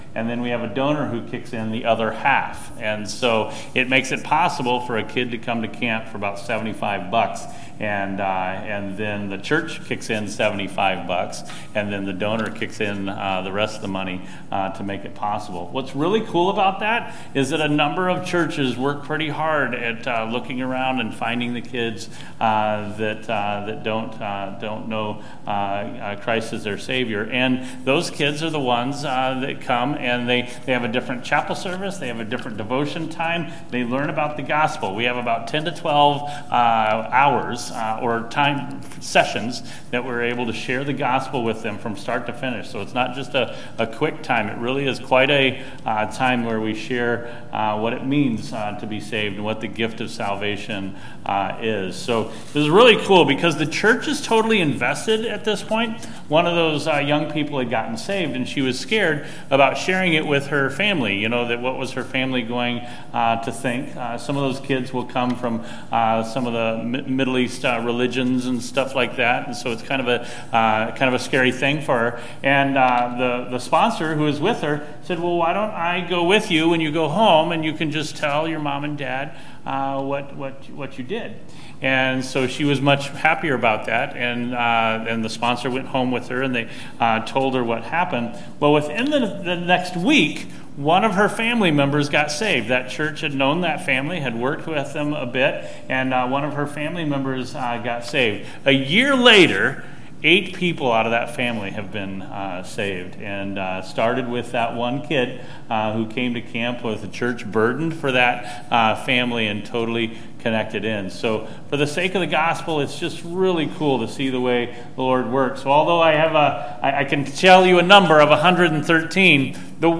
ServiceMissionary ConferenceSunday Evening